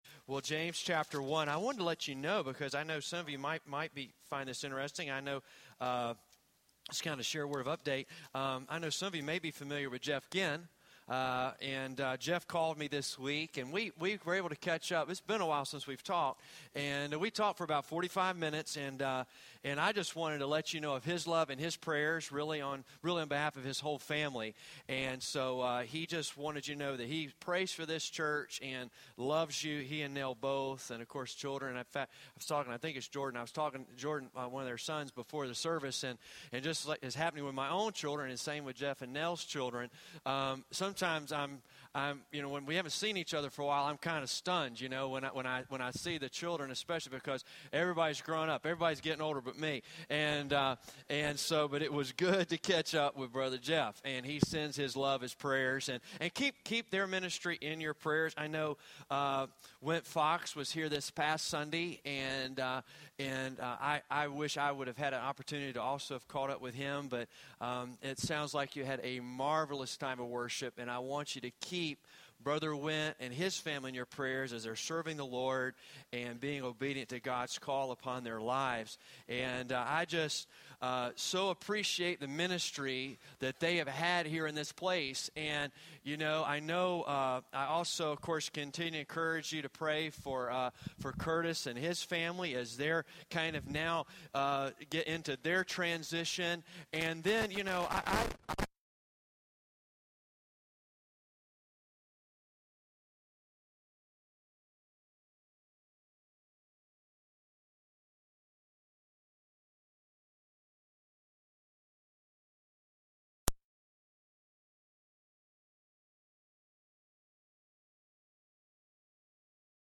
This was a sermon series preached at Mount Pleasant Baptist Church from January to March 2015.